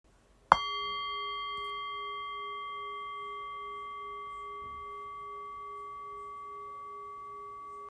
Bol chantant tibétain
tintement-bol-grave.m4a